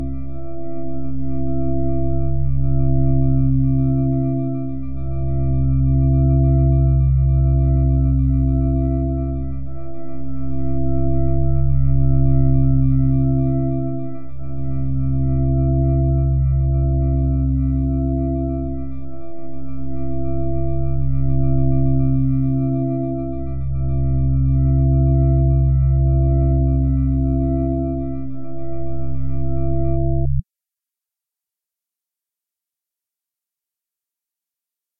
自然界 " 沙子
标签： 沙地 建设
声道立体声